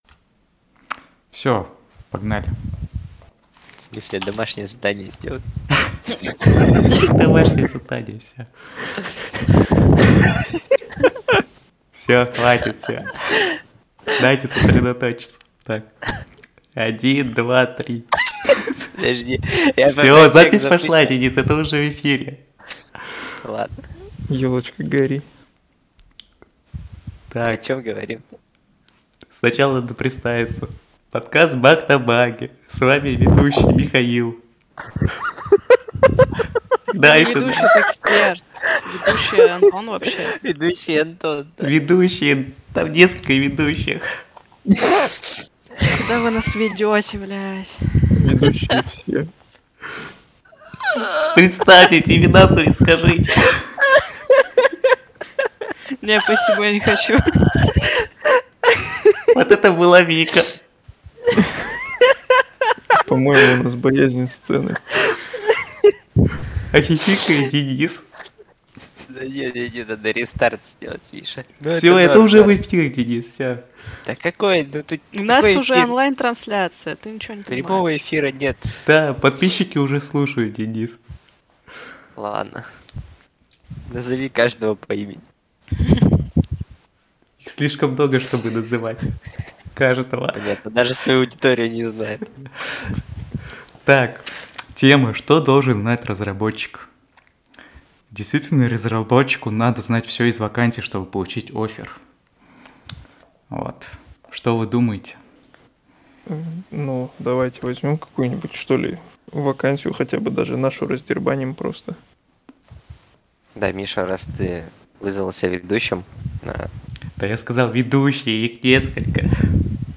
В неформальной обстановке обсудили тему, которую и так уже успели обсудить все кому не лень.